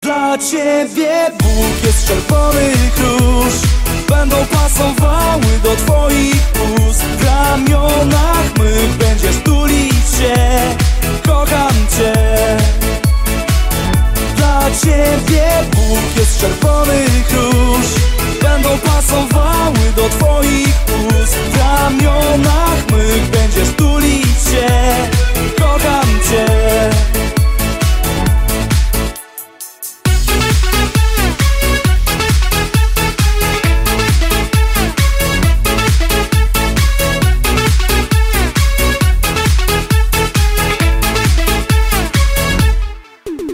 Kategorie Remixy